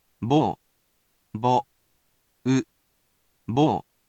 He’ll be here to help sound out these honorifics for you.